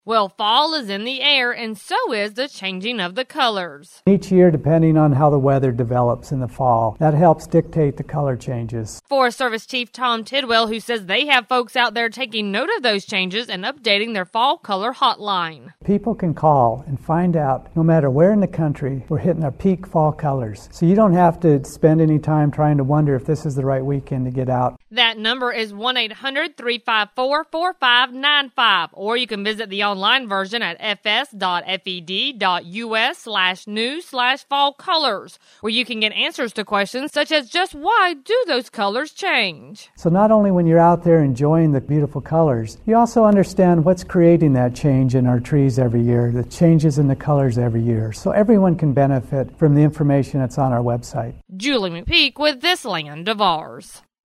Forest Service Chief Tom Tidwell tells us how to get the latest information on the fall colors.